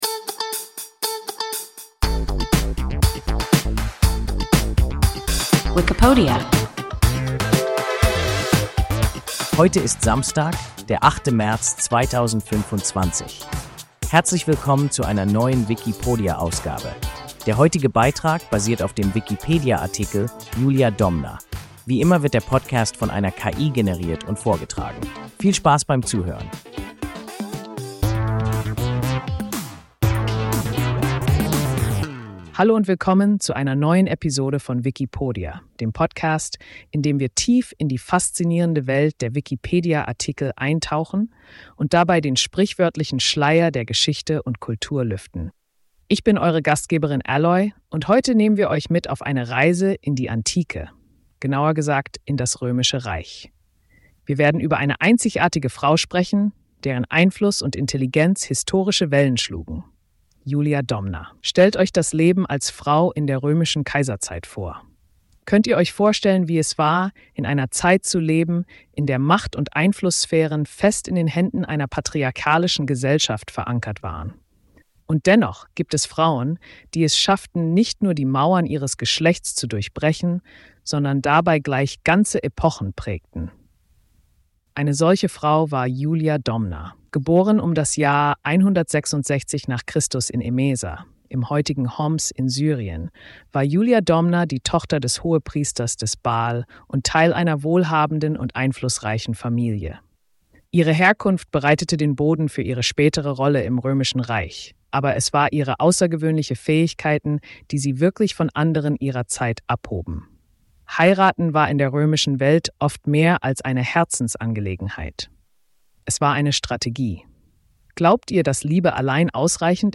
Julia Domna – WIKIPODIA – ein KI Podcast